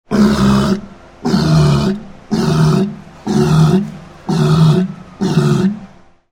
В подборке представлены характерные аудиозаписи: мощный рев и коммуникационные сигналы этих удивительных животных.
Морж ворчит недовольно